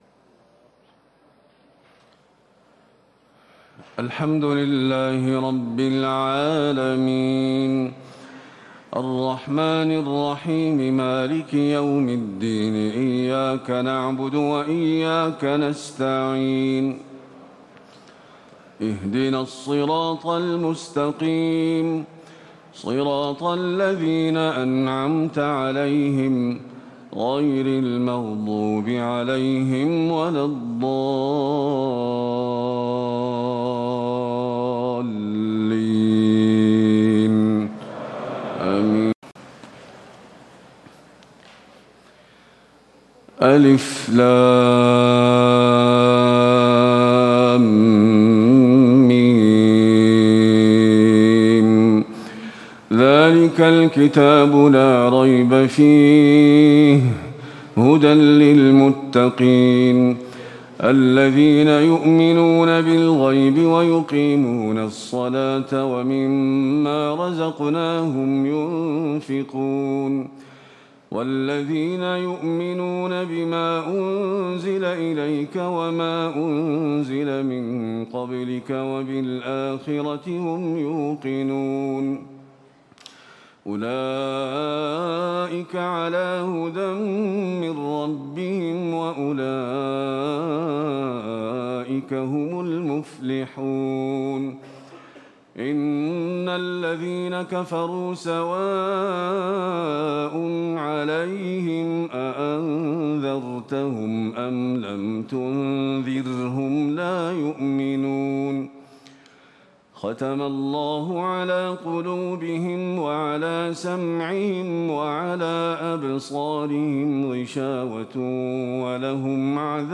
تهجد ليلة 21 رمضان 1438هـ من سورة البقرة (1-91) Tahajjud 21 st night Ramadan 1438H from Surah Al-Baqara > تراويح الحرم النبوي عام 1438 🕌 > التراويح - تلاوات الحرمين